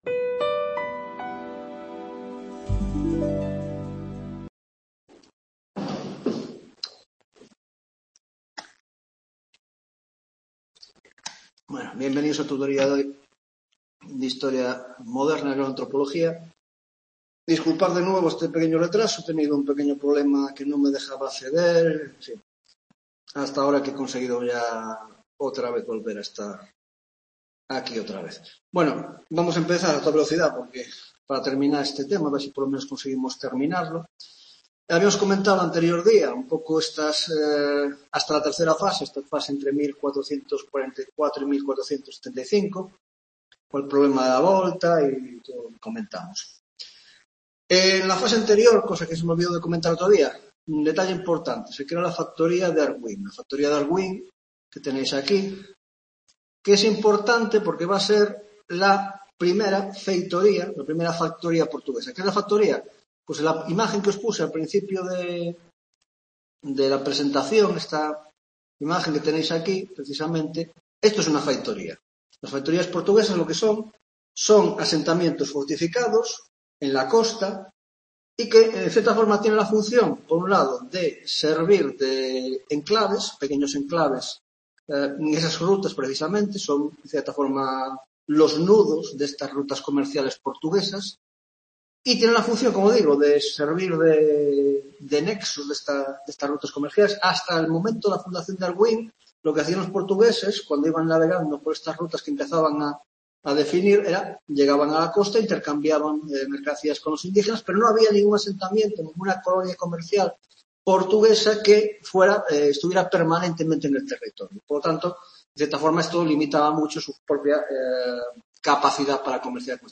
3ª Tutoría de la asignatura de Historia Moderna, Grado de Antropología: Las Exploraciones castellanas y portuguesas (2ª parte): 1) Fases y Desarrollo de la Ruta Portuguesa hacia Oriente bordeando África, 1.2) Competencia entre Portugal y Castilla y el Tratado de Alcaçovas; 1.3) La expedición de Colón.